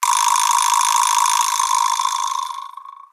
vibraslap-large02-long.wav